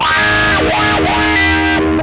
MANIC WAH 10.wav